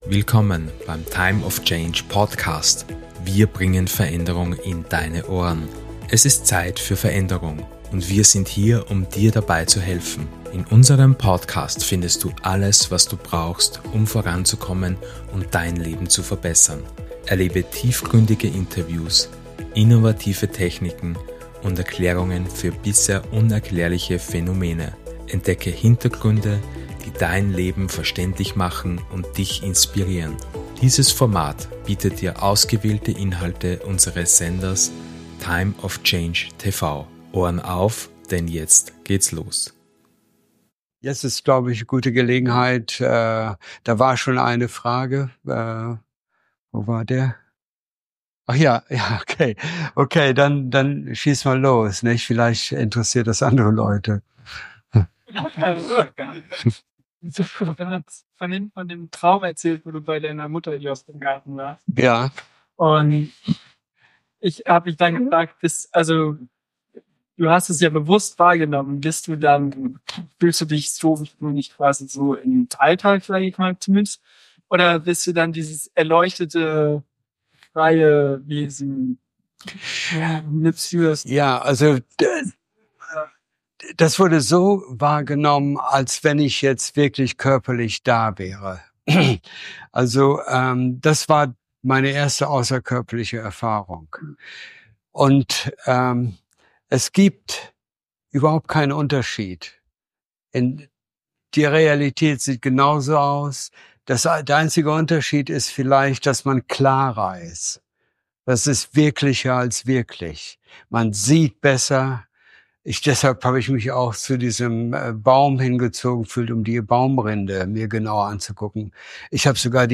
Workshop